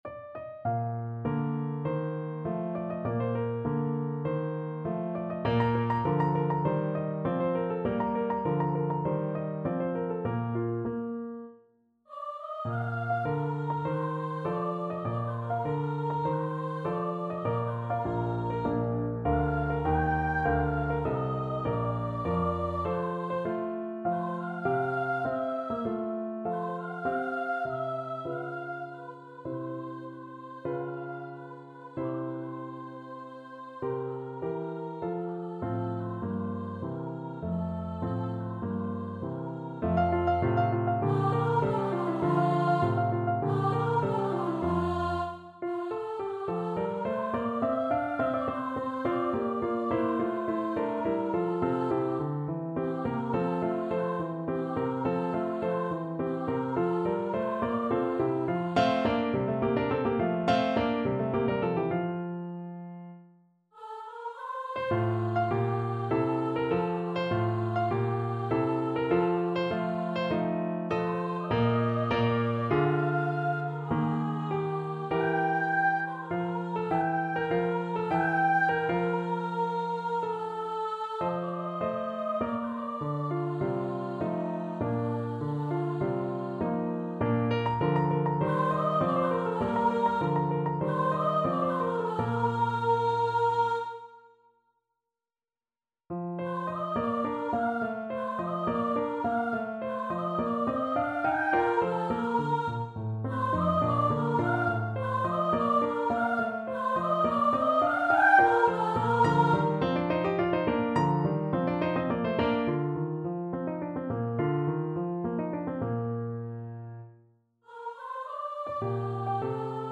~ = 50 Larghetto
2/4 (View more 2/4 Music)
Classical (View more Classical Soprano Voice Music)